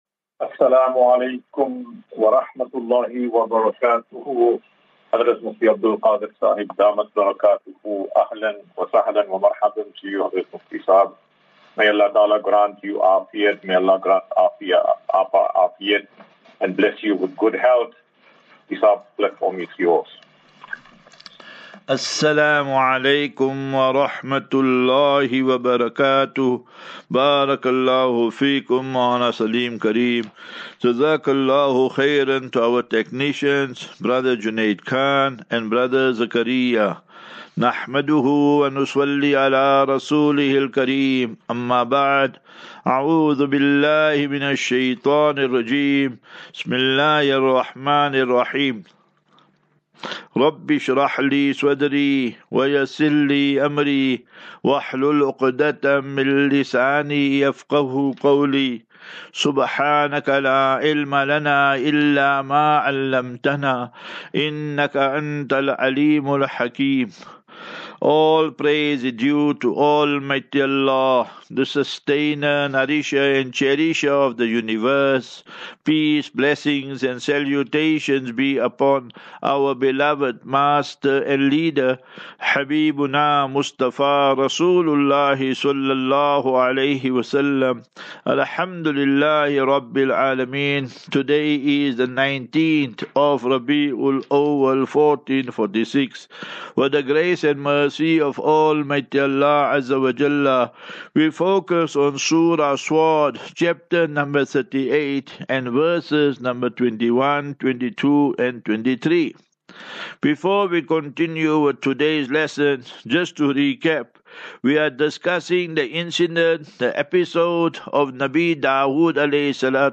23 Sep 23 September 2024. Assafinatu - Illal - Jannah. QnA